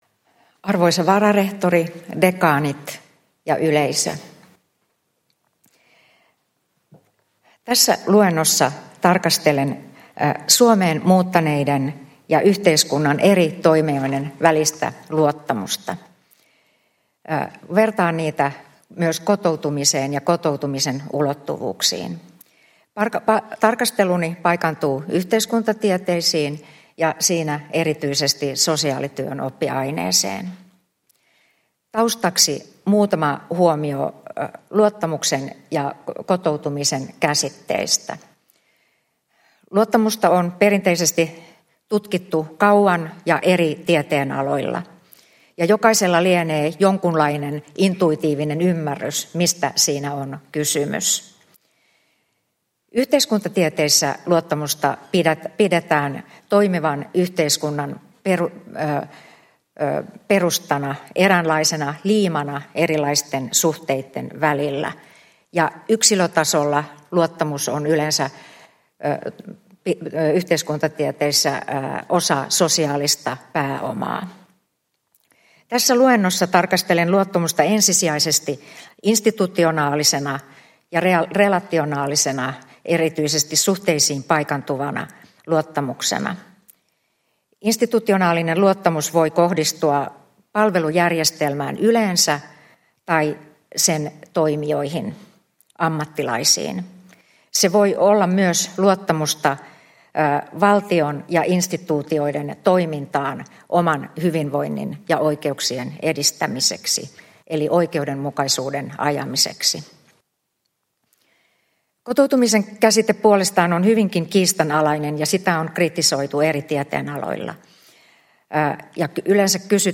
Uusien professoreiden juhlaluennot 2024